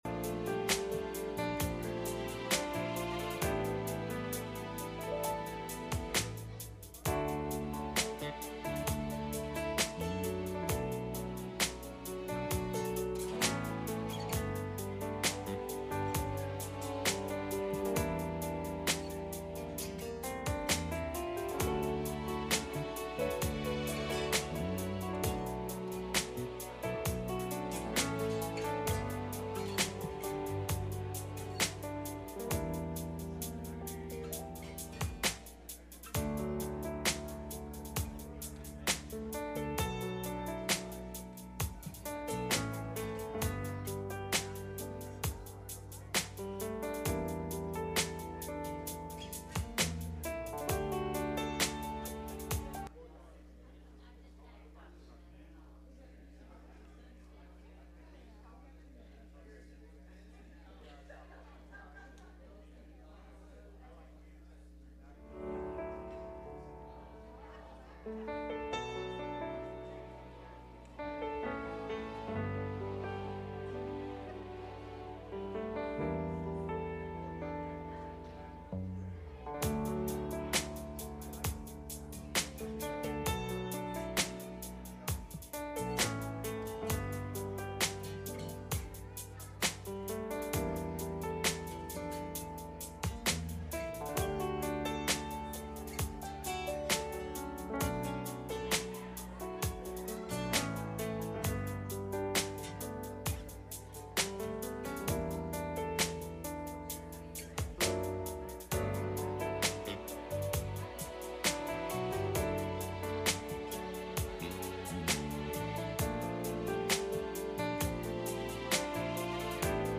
Passage: Ecclesiastes 10:8 Service Type: Sunday Morning